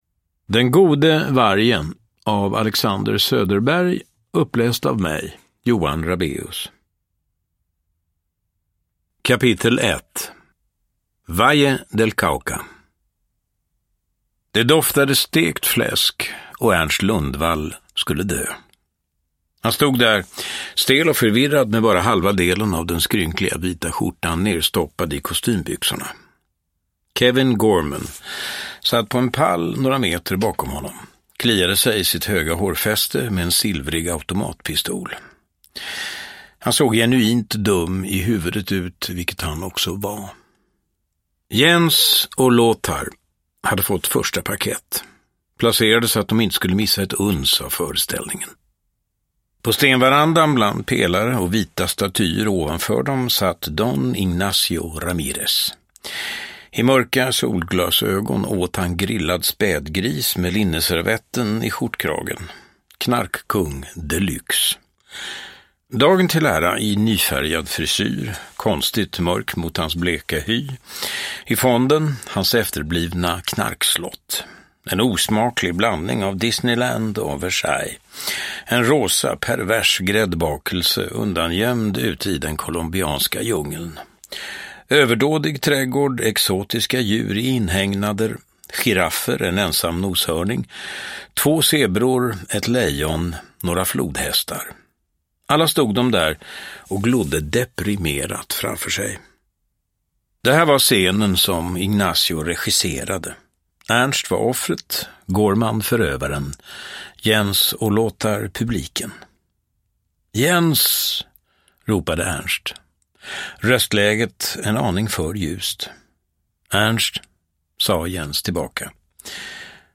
Uppläsare: Johan Rabaeus